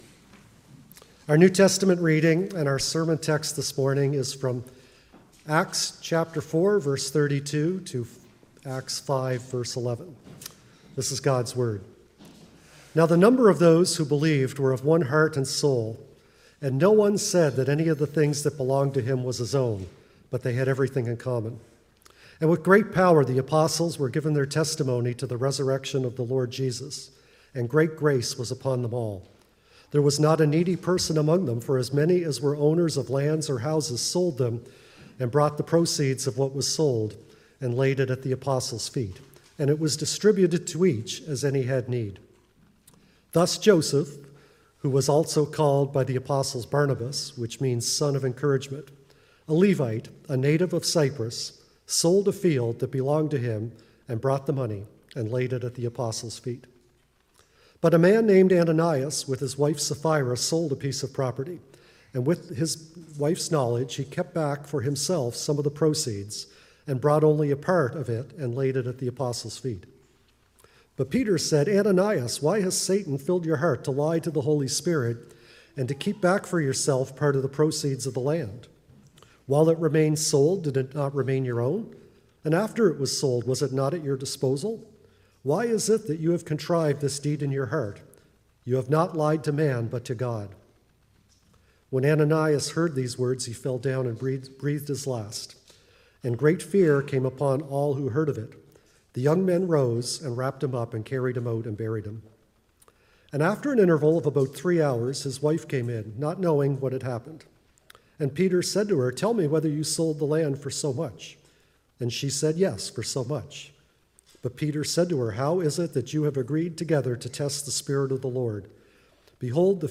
Sermon “Ananias and Sapphira”